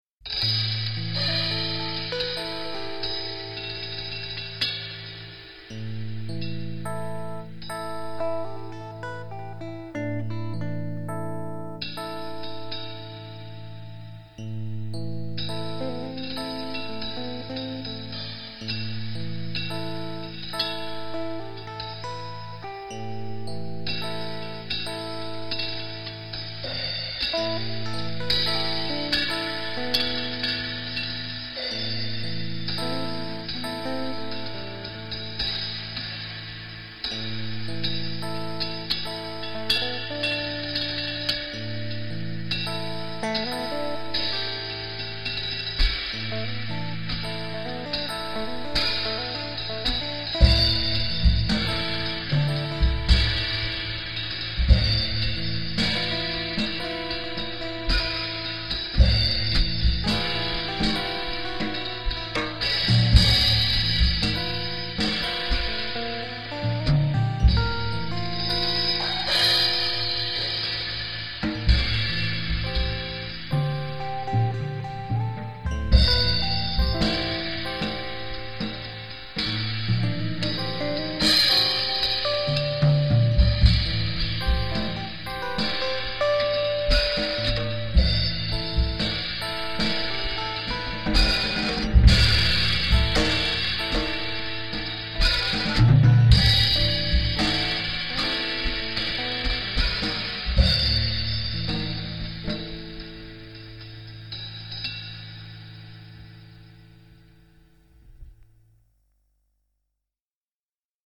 Gitarren
Schlagzeug